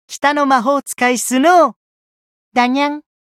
觉醒语音 北の魔法使いスノウだにゃん 媒体文件:missionchara_voice_45.mp3